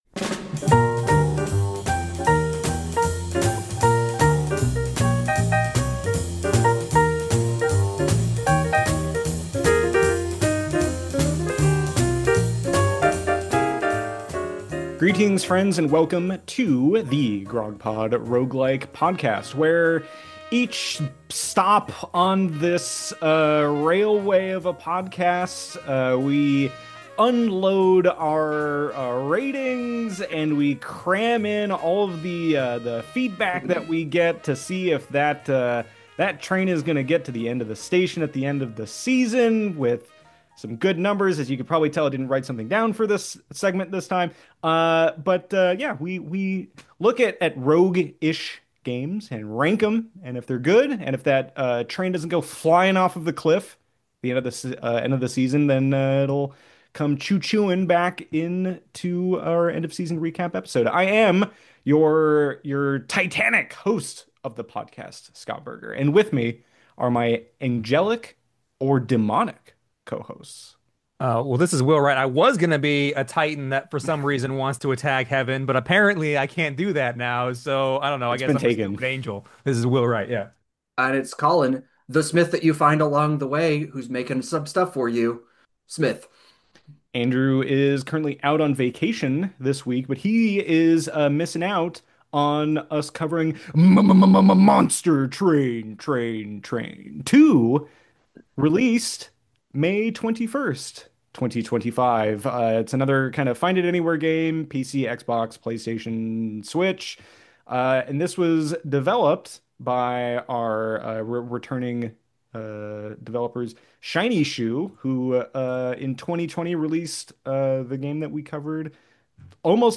One of our highest rated deckbuilder rogues arrives at GROGPOD station once again, featuring some interesting twists on the established formula. As we’ll explore more in this season, we’ll be looking closely at what makes for a good sequel while trying to make as many obnoxious monster moaning sounds at turbo speed as hum- … as monster-ly possible.